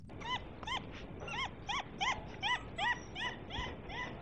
Звуки красного волка
Дружелюбный голос красного волка в стайном общении